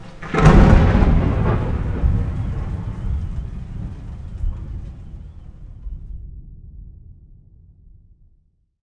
add thunder sounds
thunder_2.ogg